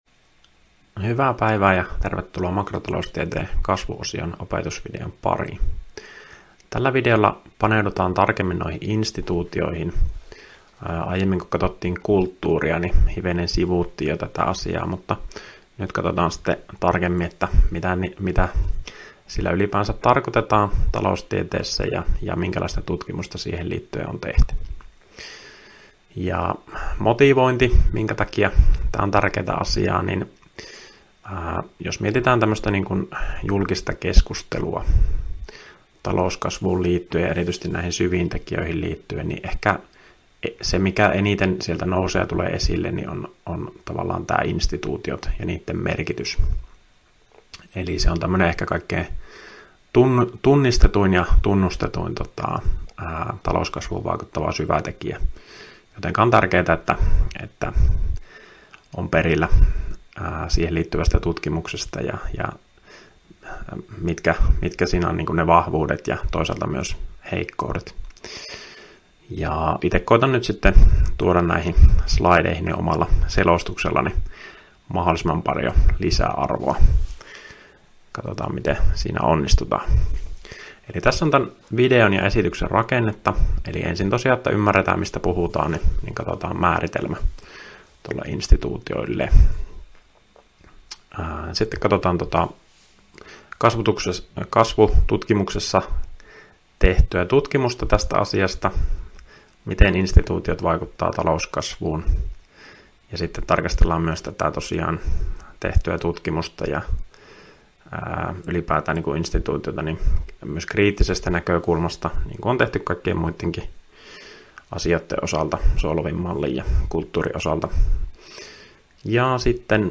Opintojakson "Makrotaloustiede I" kasvuosion 9. opetusvideo